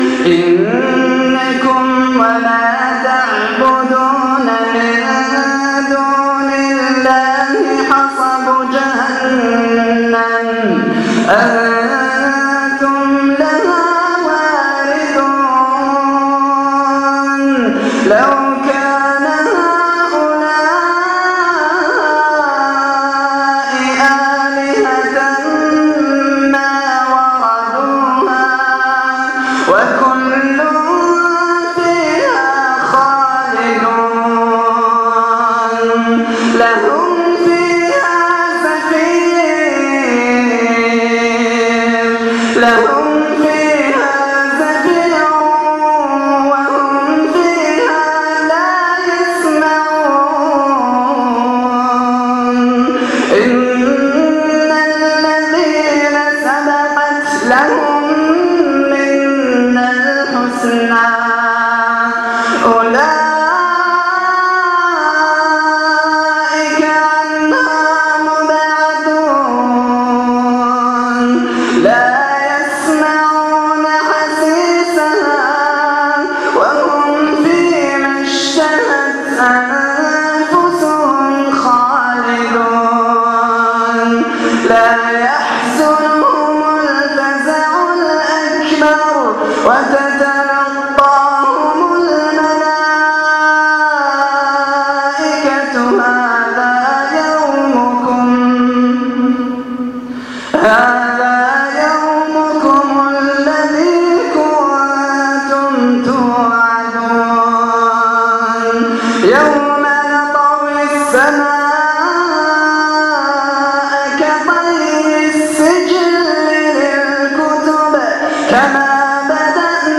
تلاوة مؤثرة